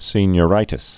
(sēnyə-rītĭs)